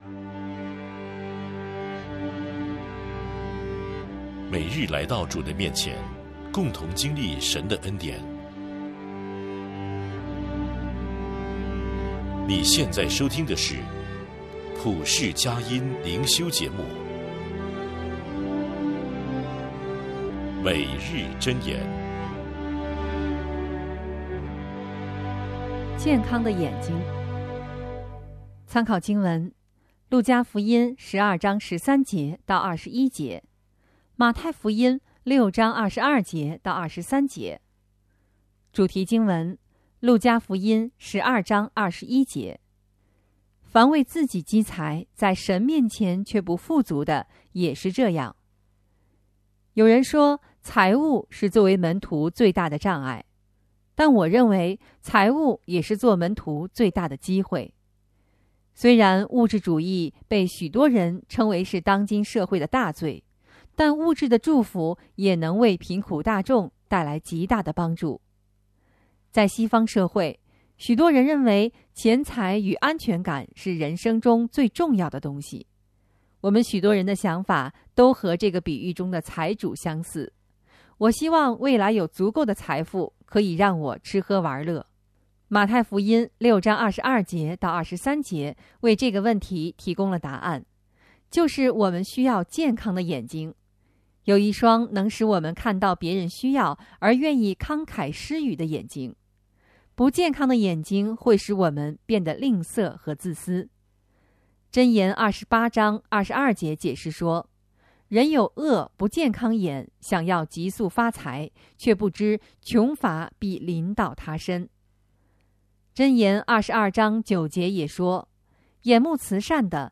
诵读